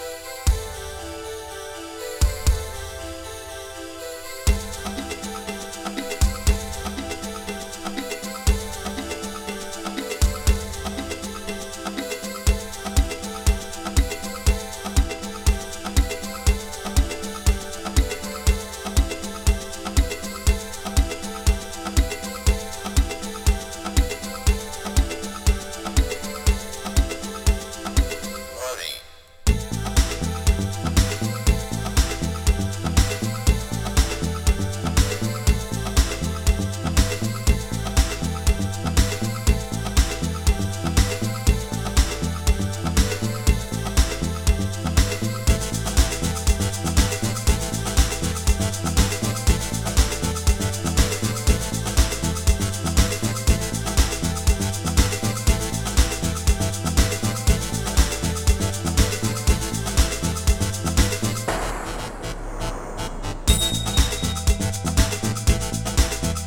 バレアリック質感が好印象な
Techno / House 80's~Early 90's レコード